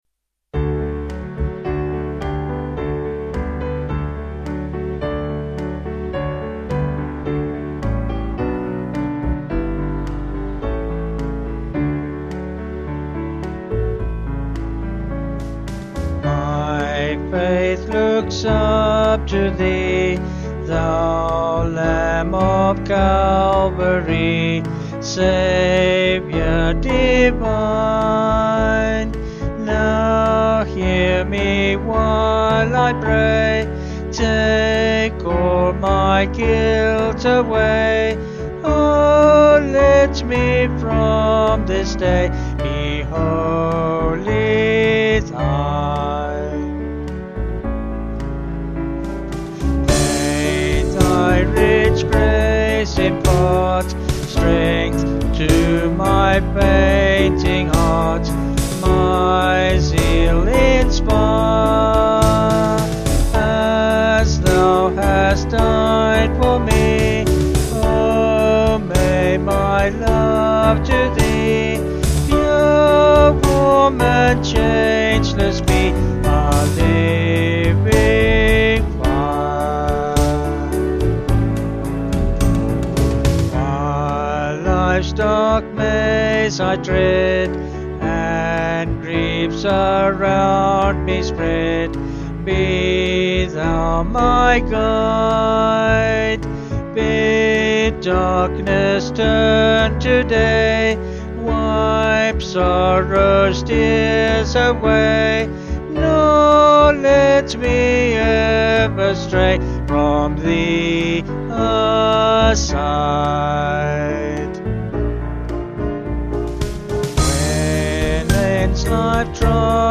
(BH)   4/D-Eb
Vocals and Band   282.9kb Sung Lyrics